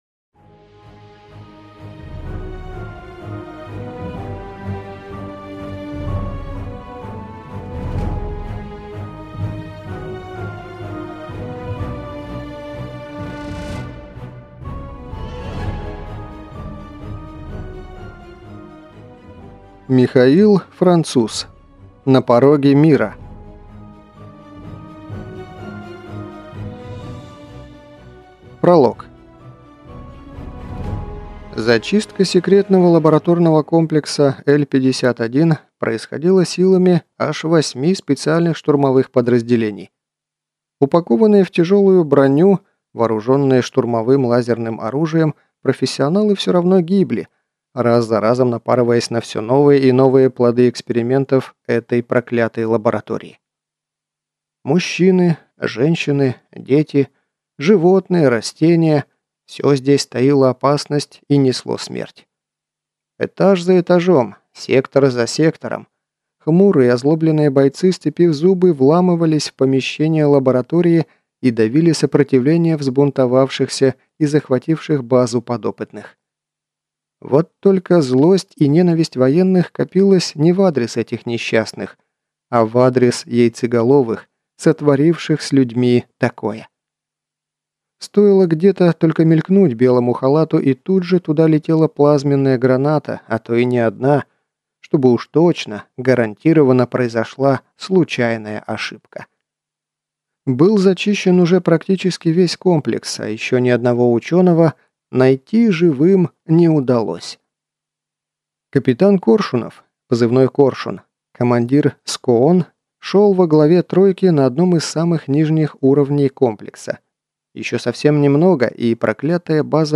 Аудиокнига На пороге мира | Библиотека аудиокниг